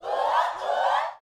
SHOUTS16.wav